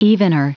Prononciation du mot evener en anglais (fichier audio)
Prononciation du mot : evener